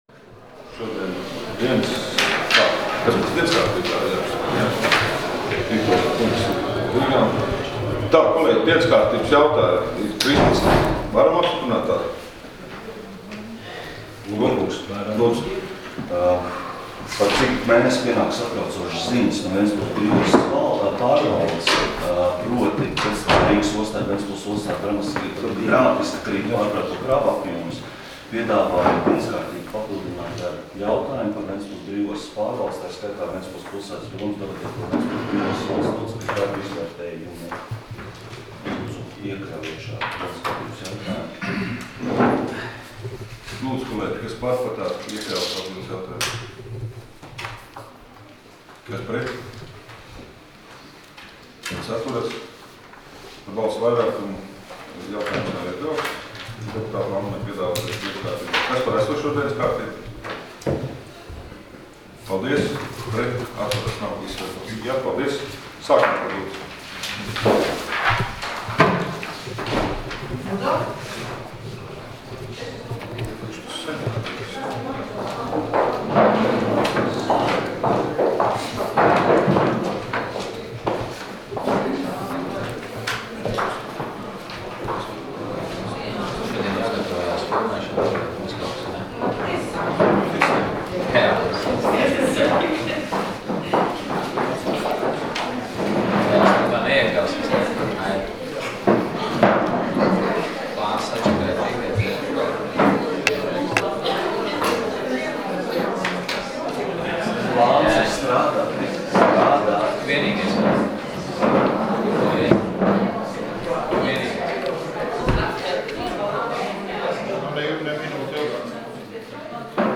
Domes sēdes 09.10.2015. audioieraksts